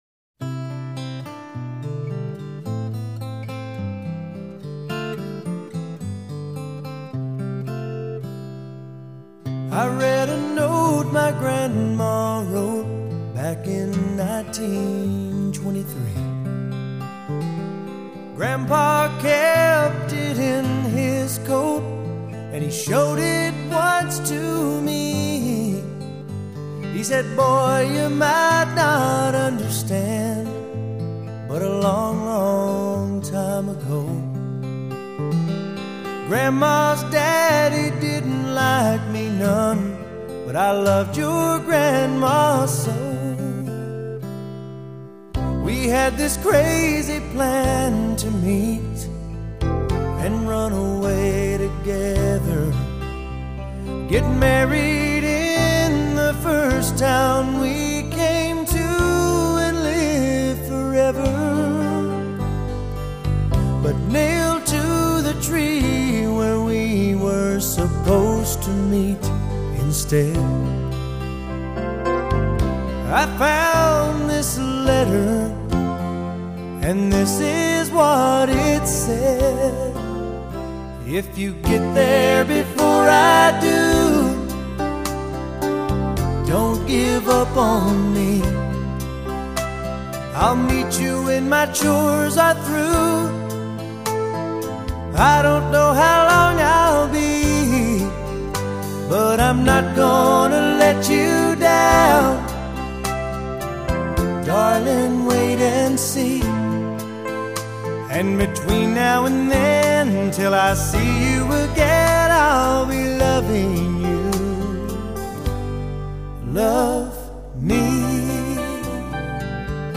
音乐类型：乡村